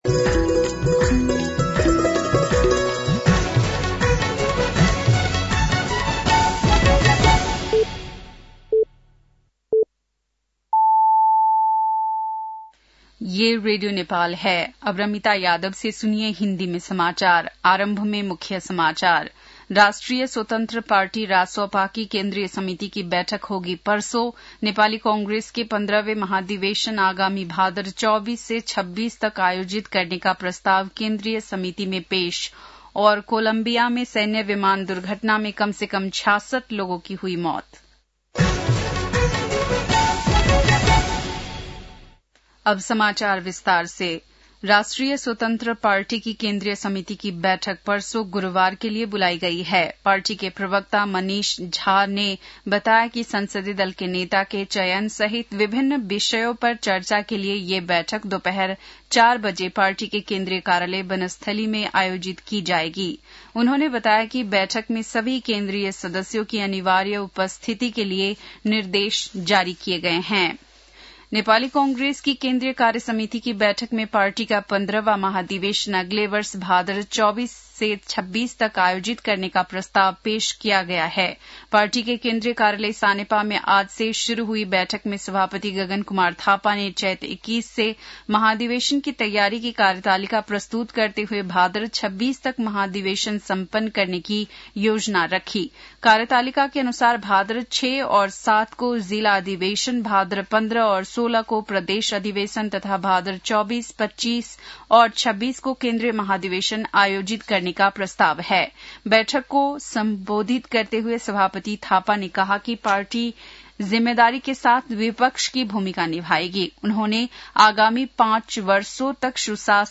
बेलुकी १० बजेको हिन्दी समाचार : १० चैत , २०८२